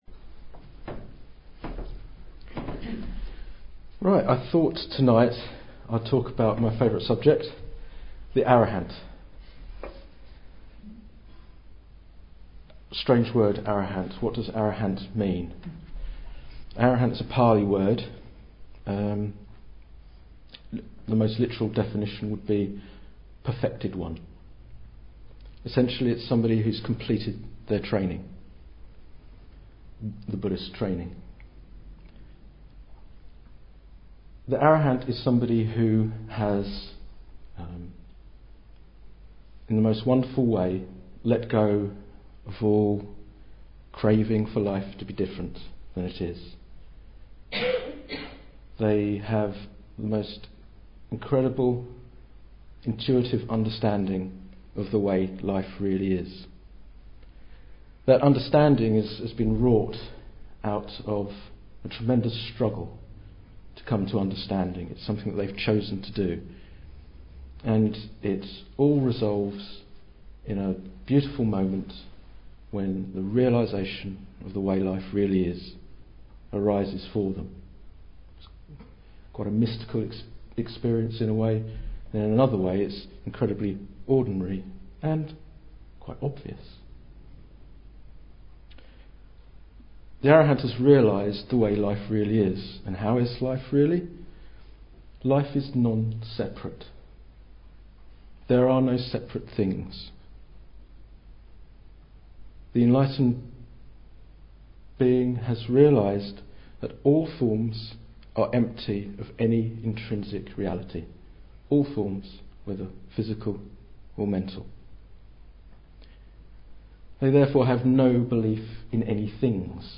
This talk describes a little of the way such individuals see life, some of the qualities needed to come to this same understanding and why arahants are worthy of the greatest reverence and honour.